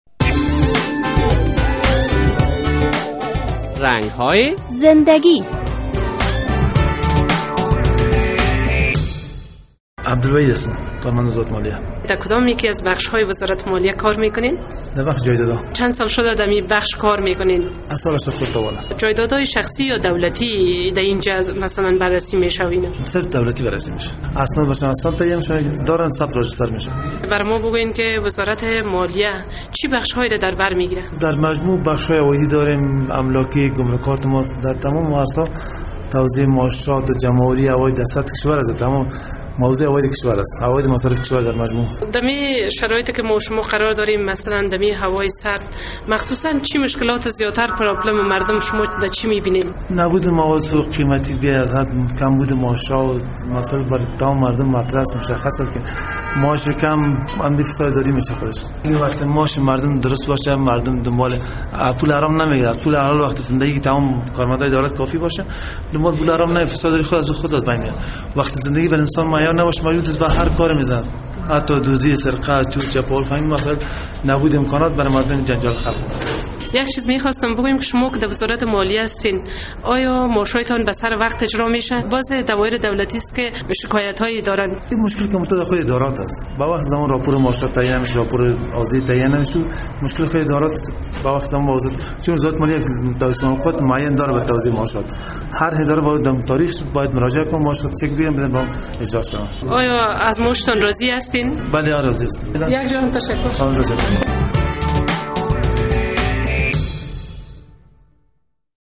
وی در صحبت با رادیو آزادی در مورد کارش معلومات داده است...